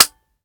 Home gmod sound weapons papa90
weap_papa90_fire_first_plr_01.ogg